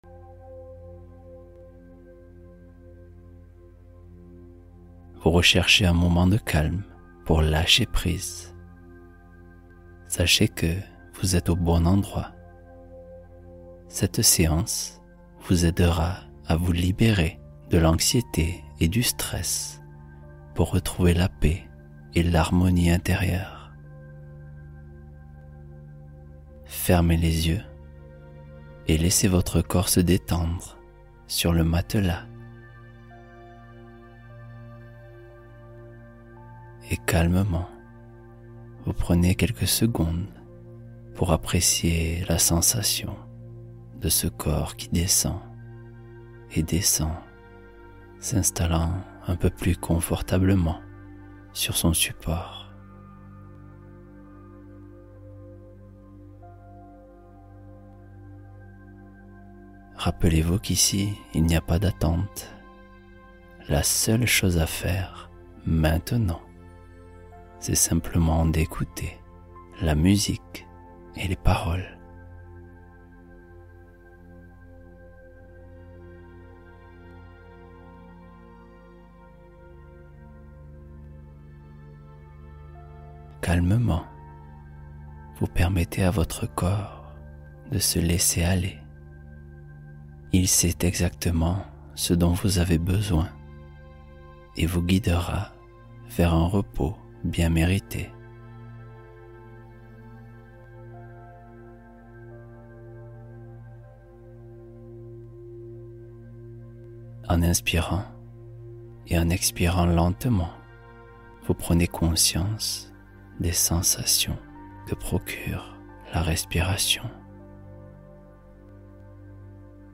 Relaxation profonde équilibrante — Retrouver calme et stabilité intérieure